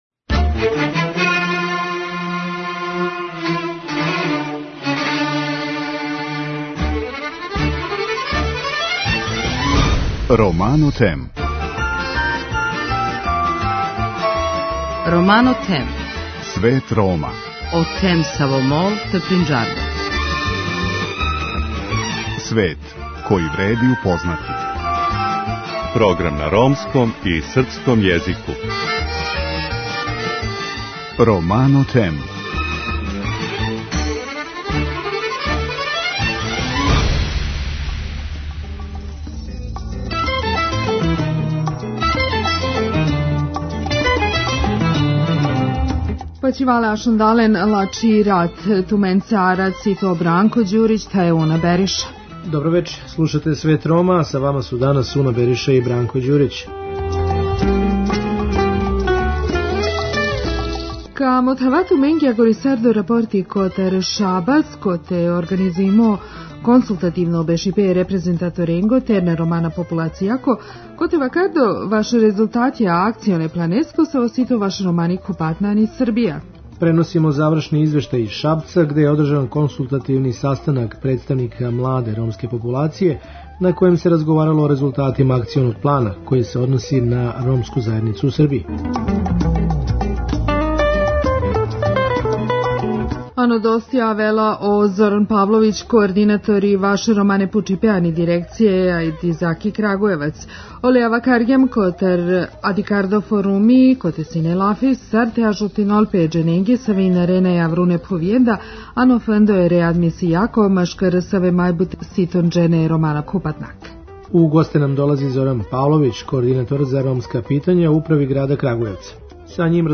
Преносимо завршни извештај из Шапца где је одржан консултативни састанак представника младе ромске популације на којем се разговарало о резултатима Акционог плана који се односи на ромску заједницу у Србији.